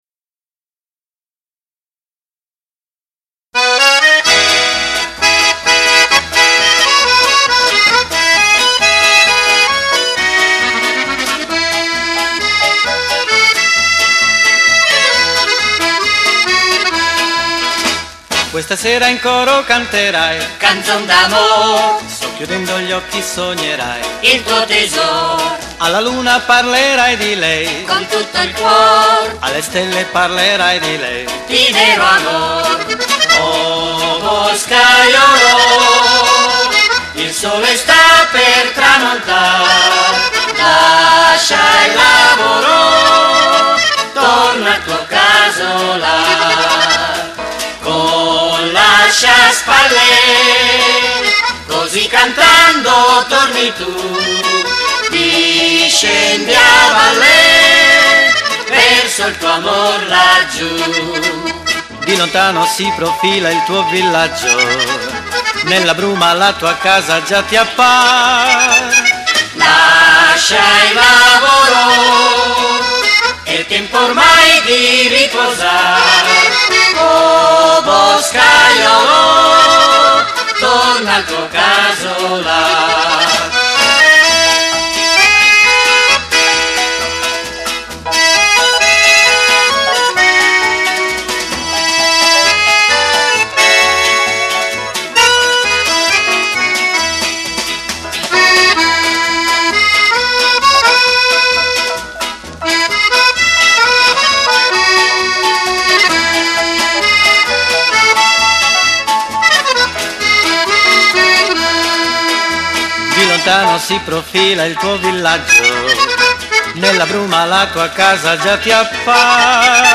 RITMO ALLEGRO